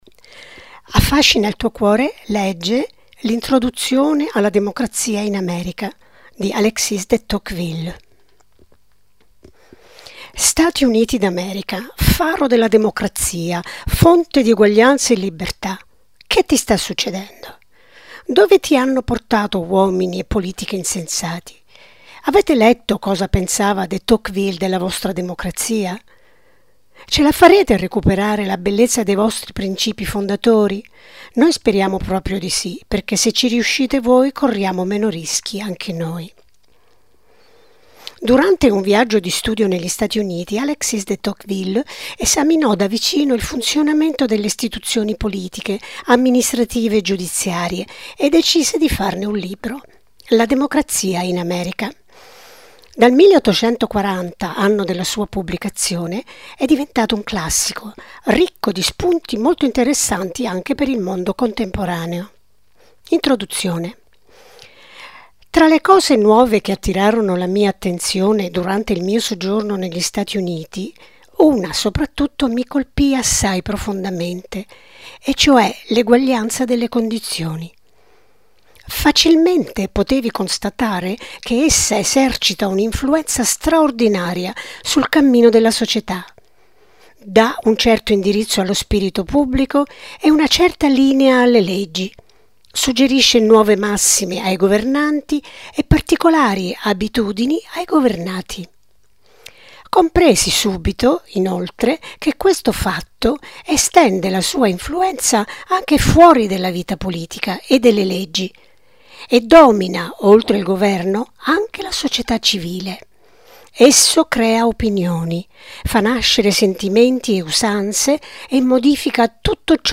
Ad alta voce